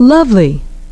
loveliness <)), lovableness <)), we see that the stress is constant on the first syllable.